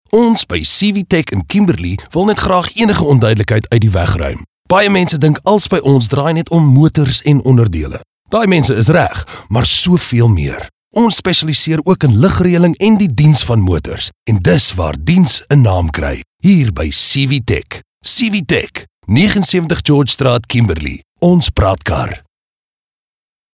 authoritative, Deep, raspy